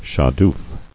[sha·doof]